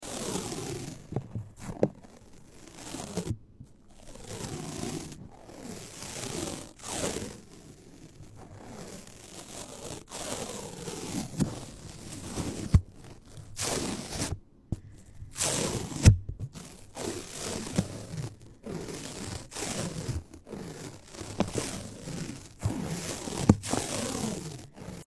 Crinkly tape triggers to help sound effects free download
Crinkly tape triggers to help you relax.